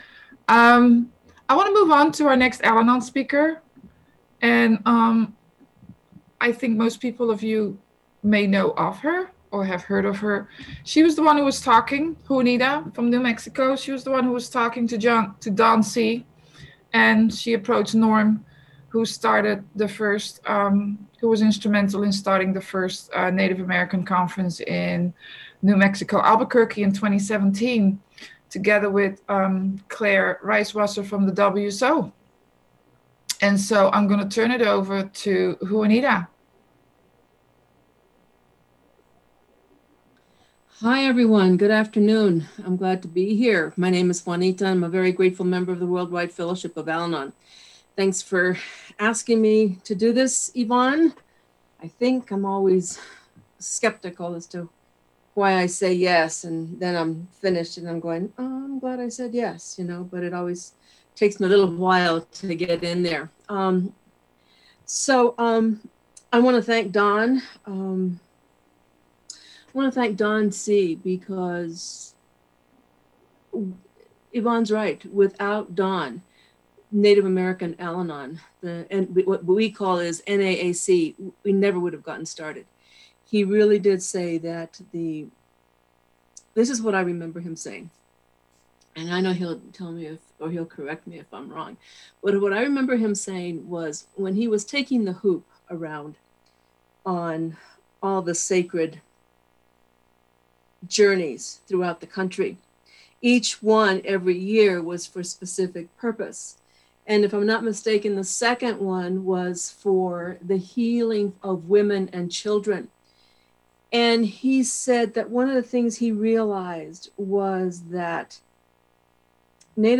American Indian Conference - AWB Roundup Oct 17-18